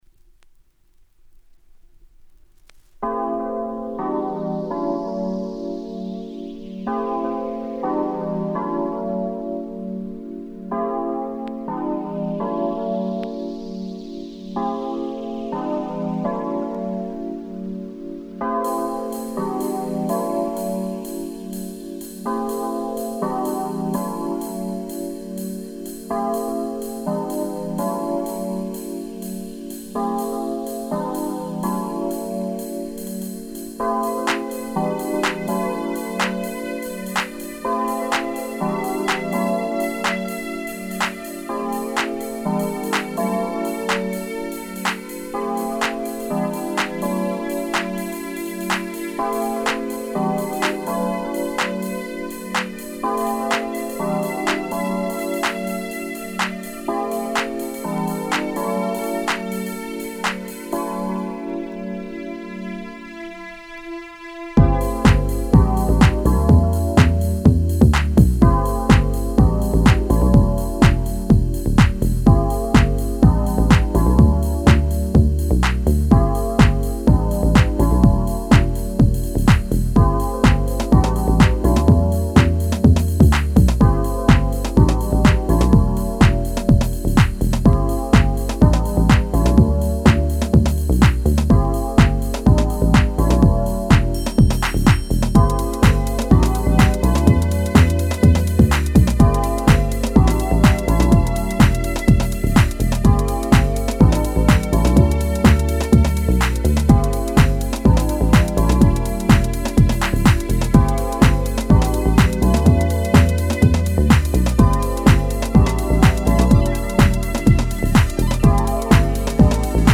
I've put up a mix done by a friend of mine from NYC.
Both play NY style deep house (not super vocal-heavy).